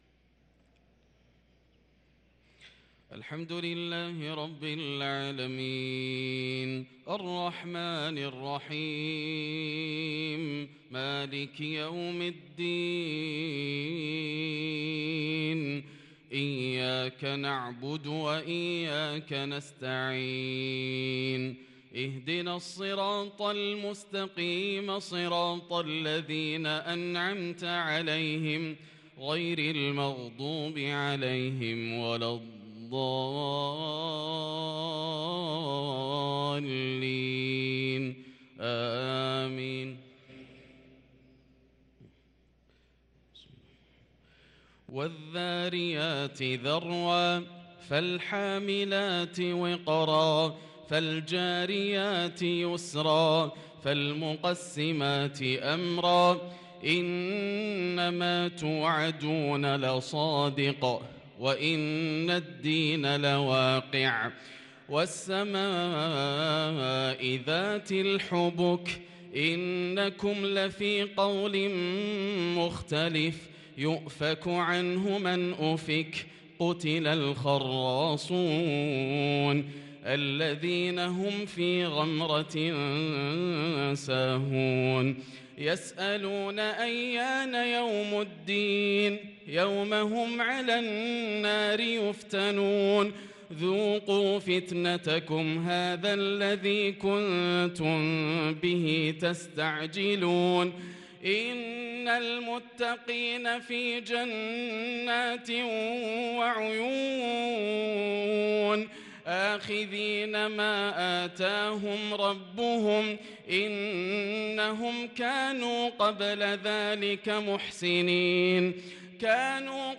صلاة العشاء للقارئ ياسر الدوسري 25 شعبان 1443 هـ
تِلَاوَات الْحَرَمَيْن .